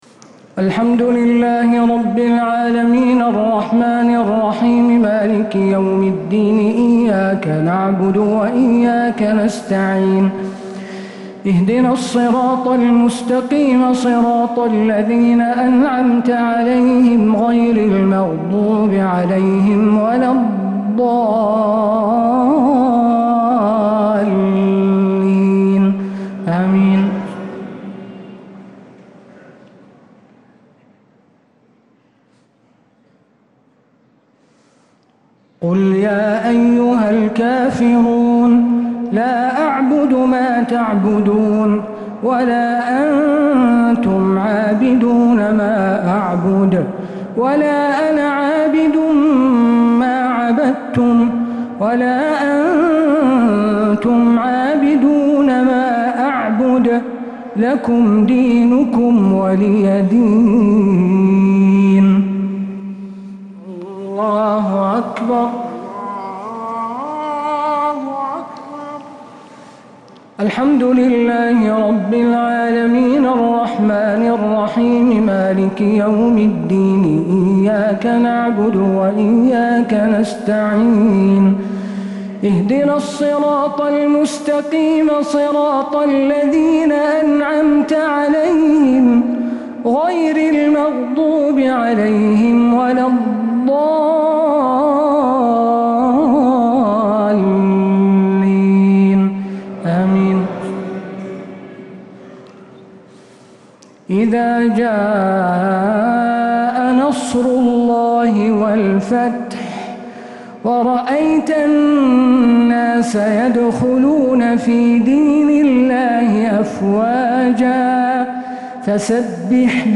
صلاة الشفع و الوتر ليلة 29 رمضان 1446هـ | Witr 29th night Ramadan 1446H > تراويح الحرم النبوي عام 1446 🕌 > التراويح - تلاوات الحرمين